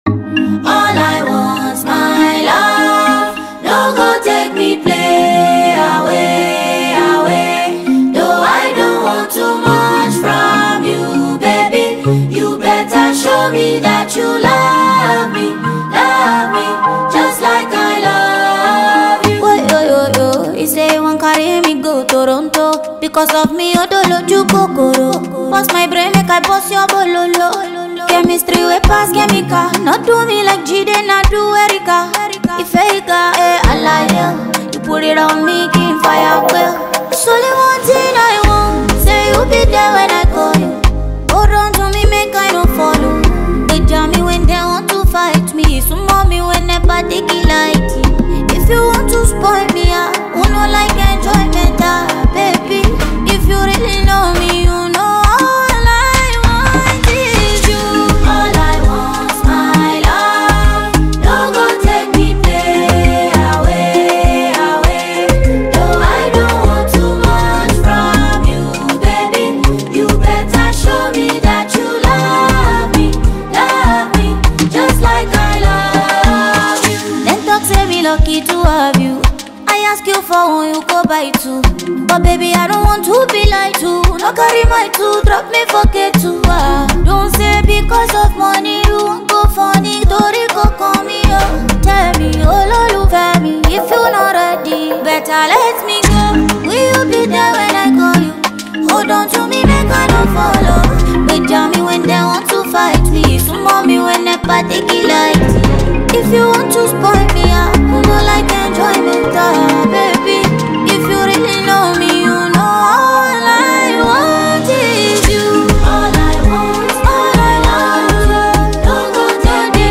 Fabulous Nigerian female singer and composer
This energetic masterpiece